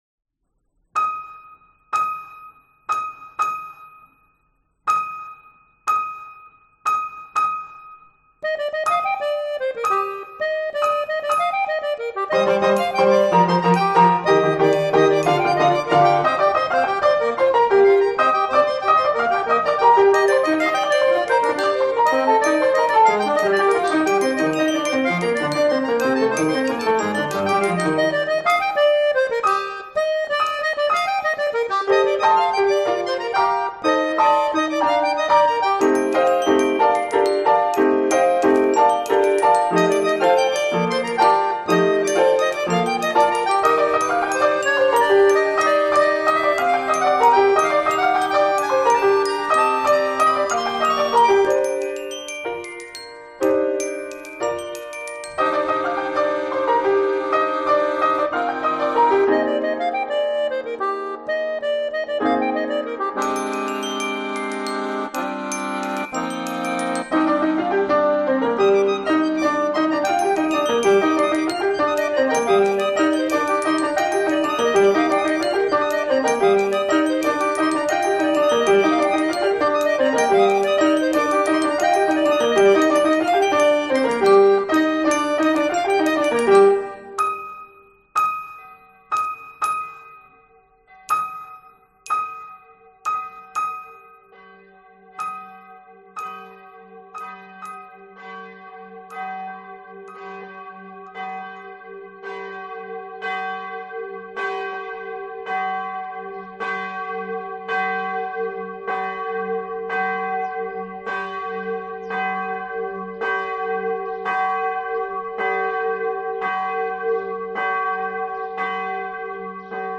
Fresque sonore
La fresque sonore est le portrait audio subjectif d’un territoire, d’une ville, d’un village, d’un quartier, d’une rue. Les enregistrements in situ et les témoignages récoltés durant le temps de maraude seront mis en relief par des compositions musicales originales, écrites pour l’occasion et inspirées par les rencontres, les lieux, l’atmosphère.
D’autres éléments pourront venir se greffer à la fresque comme des lectures d’extraits de romans, de récits ou de poésie, entrant alors en résonnance avec la matière glanée.
fresque-mon-portrait-sonore-de-la-copary.mp3